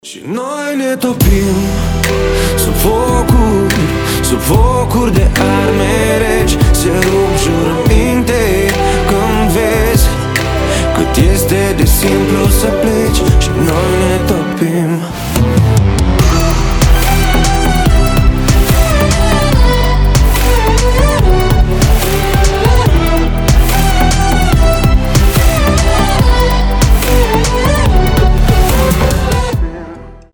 поп
мелодичные
красивый мужской вокал
медляк